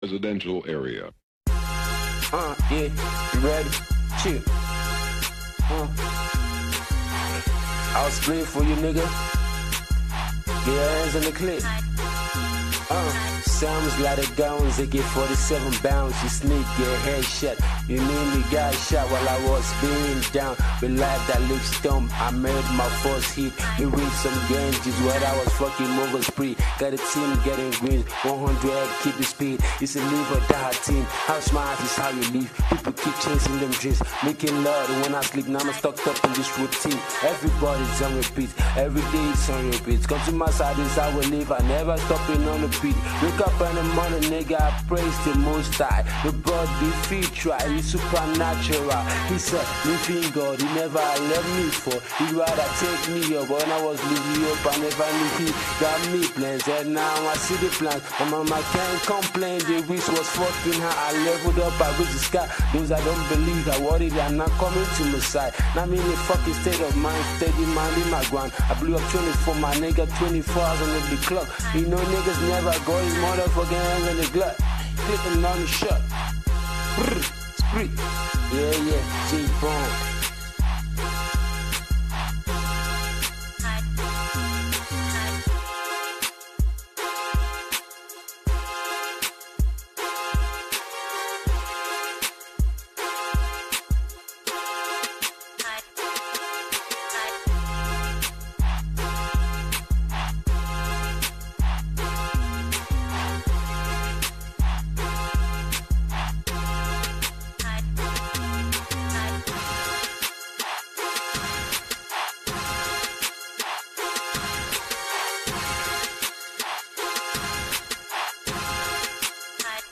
up and coming Afrobeats musician
freestyle